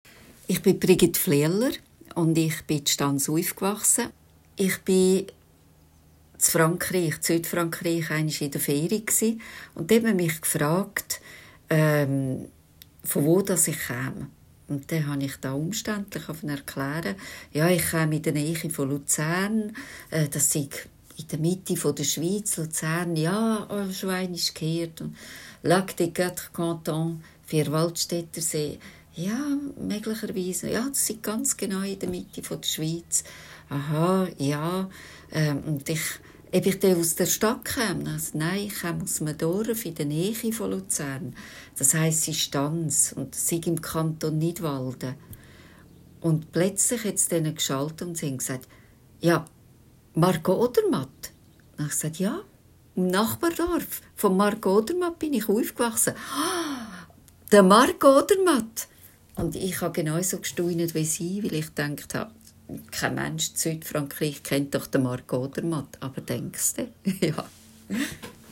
Stanser expressions